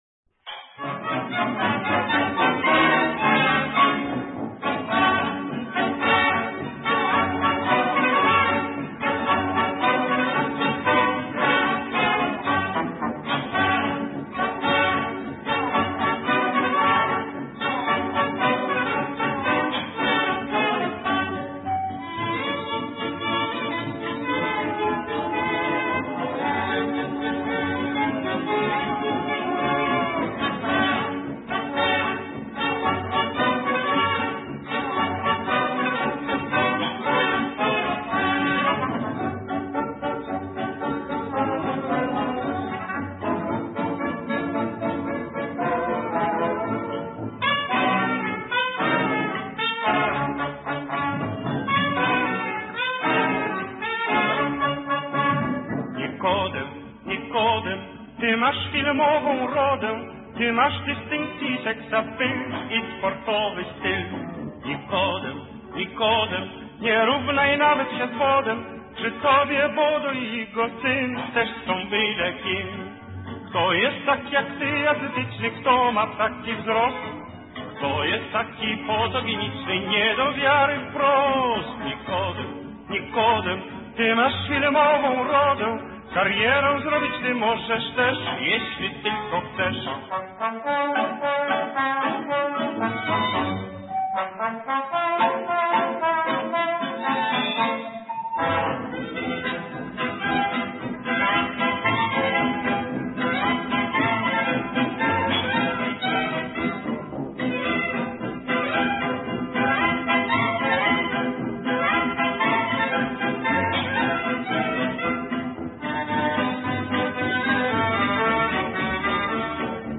польский фокстрот